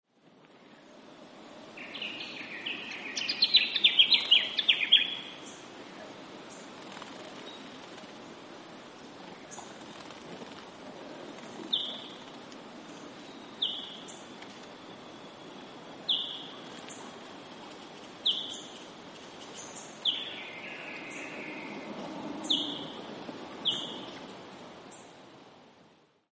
Yellow-faced Honeyeater - Lichenostomus chrysops
Voice: a loud, cheerful 'chick-up'.
Call 1: several loud calls; a White-browed Scrubwren starts scolding at the end of the recording.
Yellow_faced_Hon.mp3